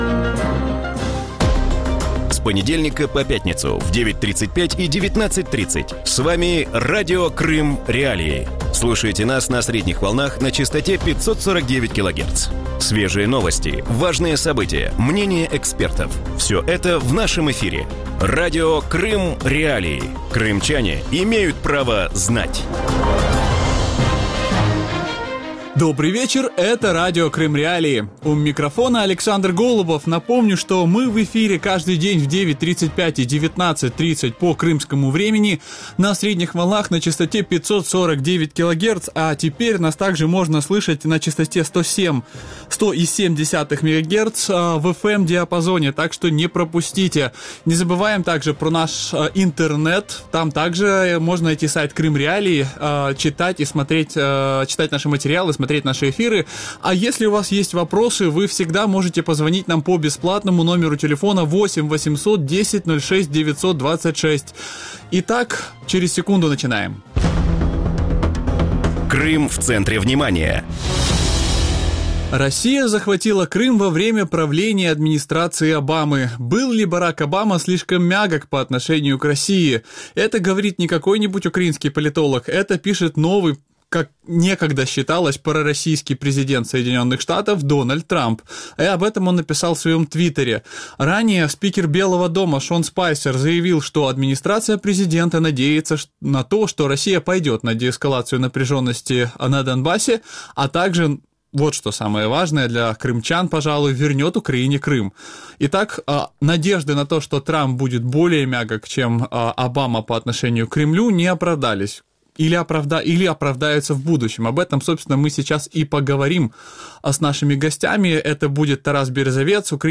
В вечернем эфире Радио Крым.Реалии говорят о заявлениях президента США Дональда Трампа по поводу возвращения Крыма. Сформулировала ли новая администрация президента США позицию по Крыму, какой будет стратегия взаимодействия Белого дома с Кремлем и снимут ли с России санкции?